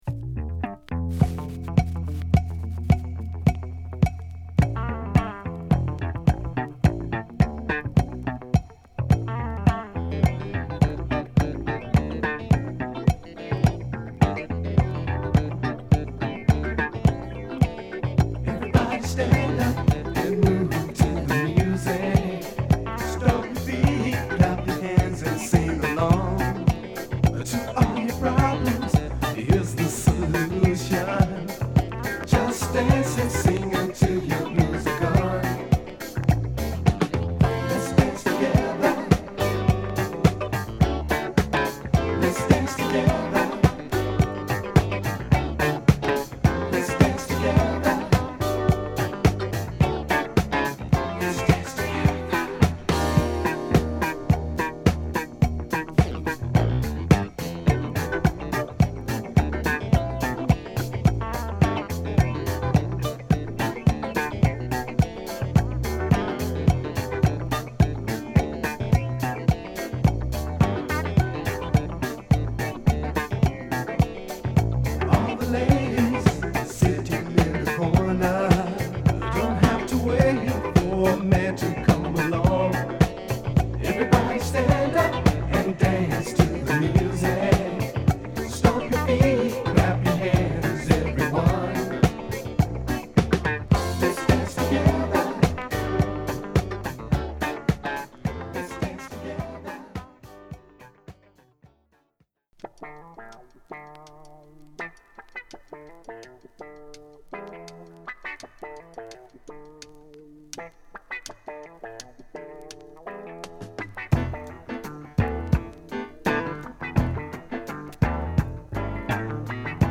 哀愁サックスが響く粘着度高いファンクチューン